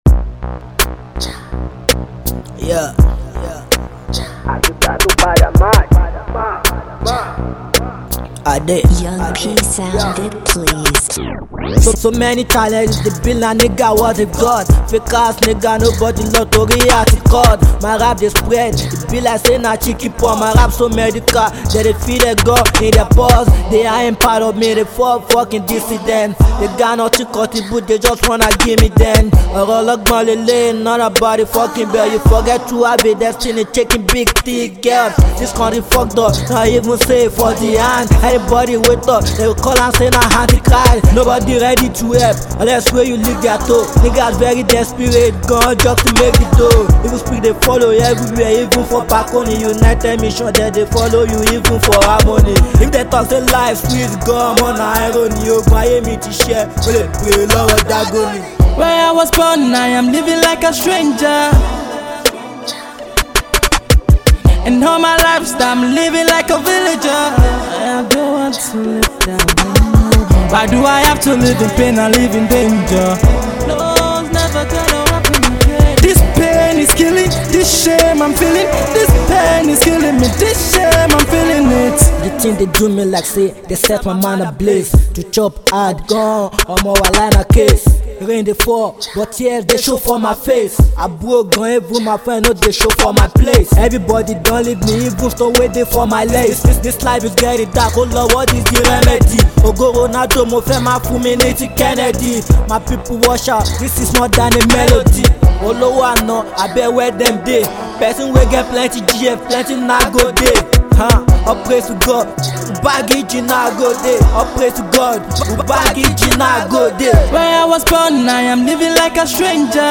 Fast rising Nigerian rapper